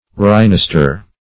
Search Result for " rhinaster" : The Collaborative International Dictionary of English v.0.48: Rhinaster \Rhi*nas"ter\, n. [NL., fr. Gr.